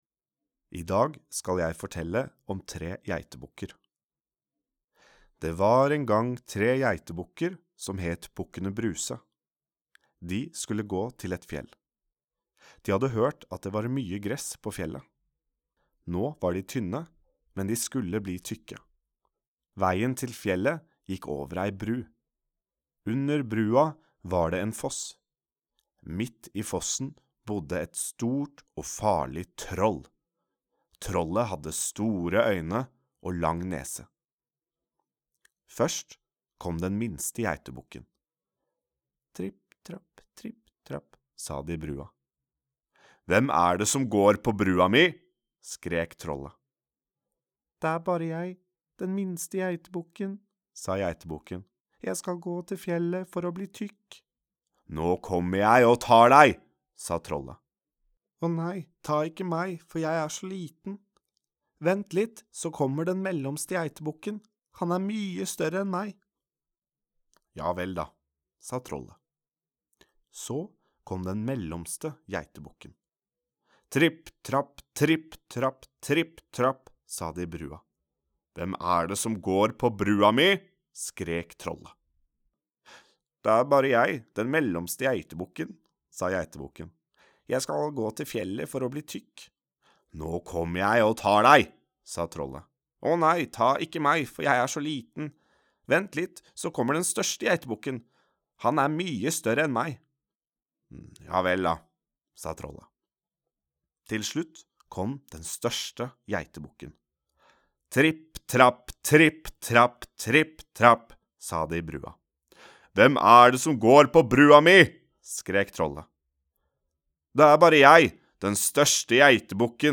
I dag forteller han om Bukkene Bruse.